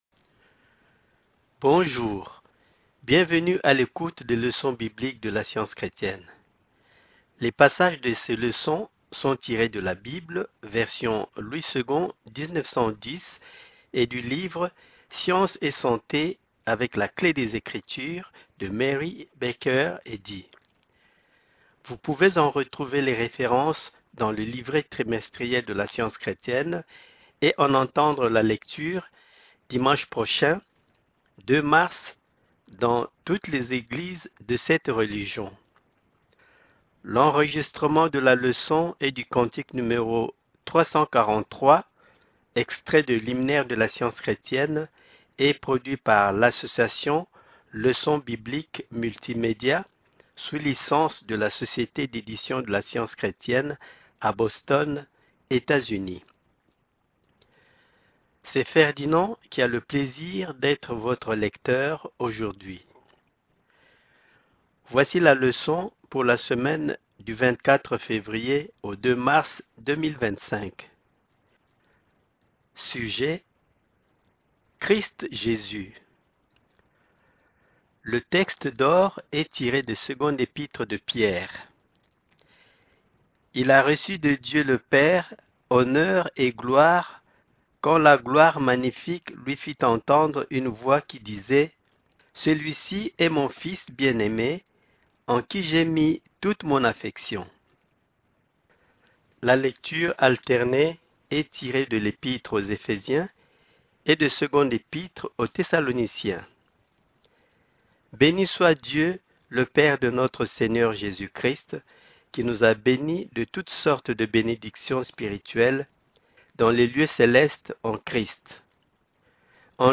Leçon témoin